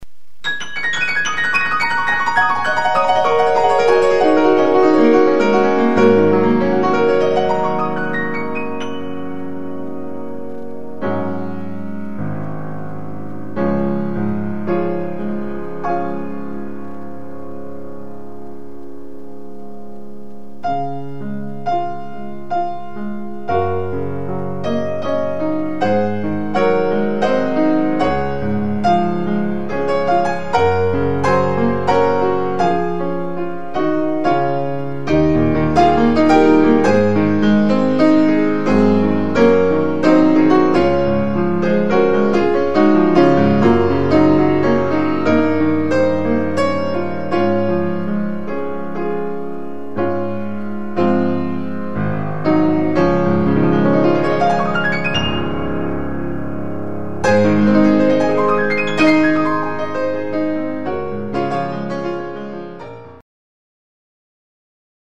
Piano CDs